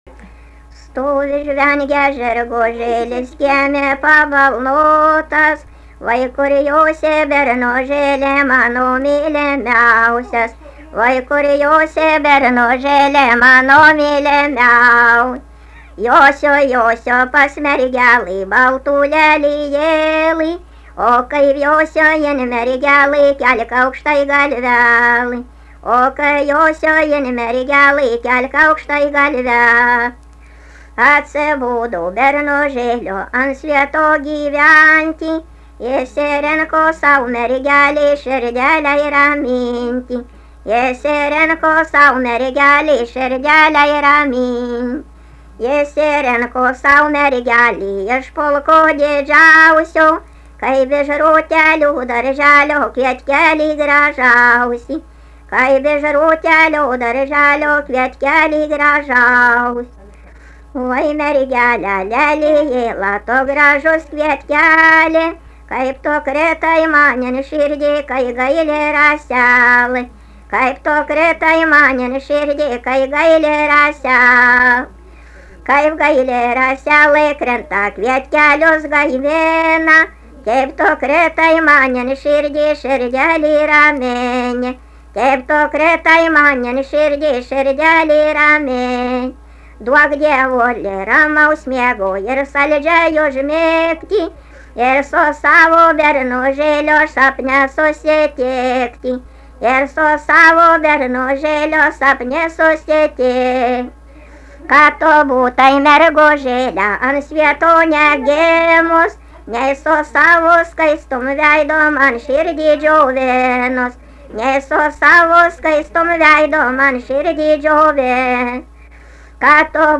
Dalykas, tema daina
Erdvinė aprėptis Pauosupė
Atlikimo pubūdis vokalinis